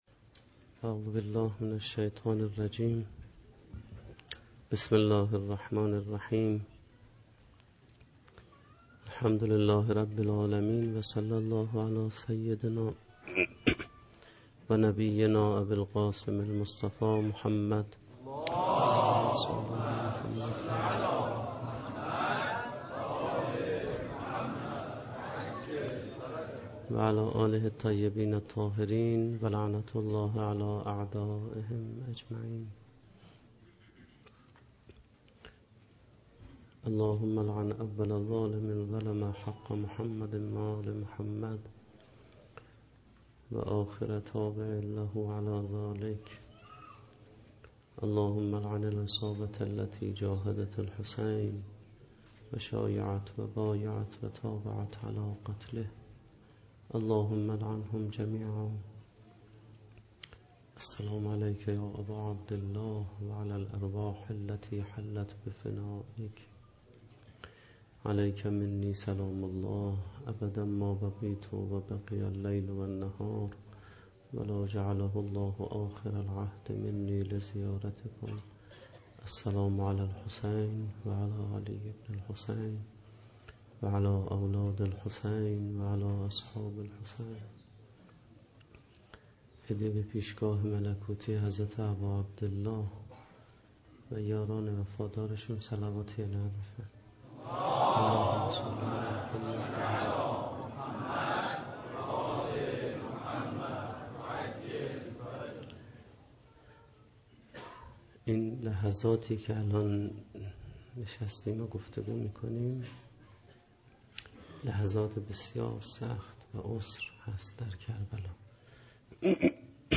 سخنرانی
ظهر عاشورای 1436